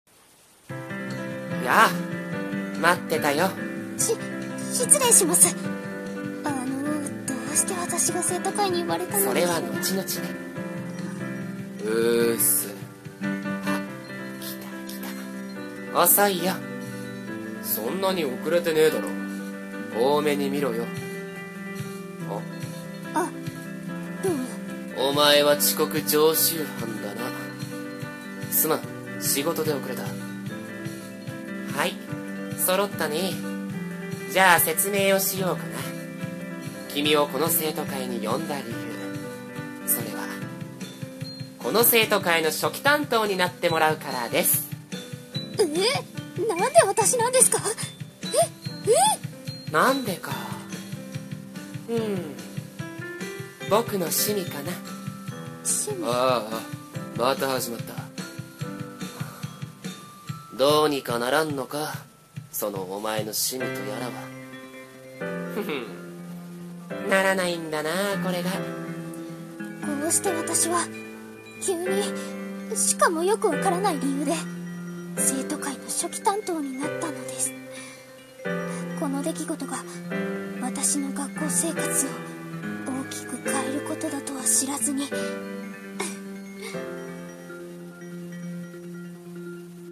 声劇(掛け合い声面接)〜生徒会へ〜